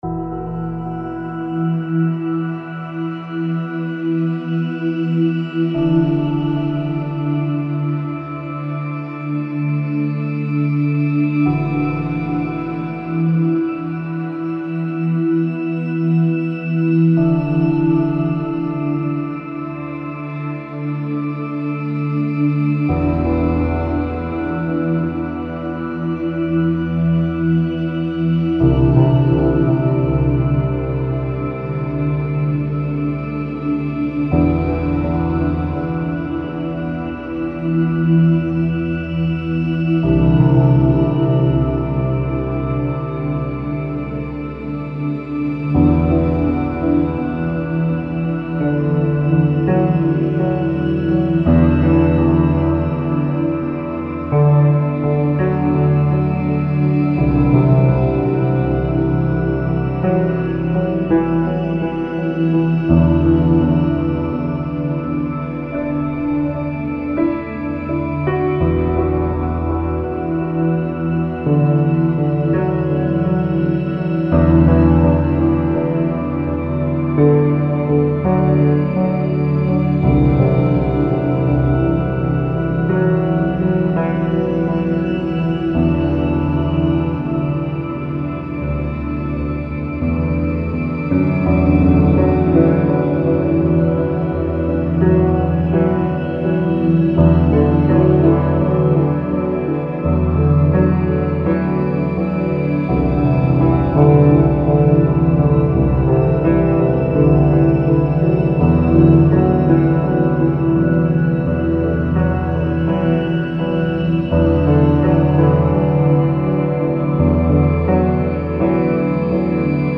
I've gone for a bit more of an ambient feel
now, but the piano is still quite dominant. The MIDI fucks out for a couple bars at some point...2 minutesish I think, and its live recording at about the time of the positive change, so timing is a bit off - made evident by the delay.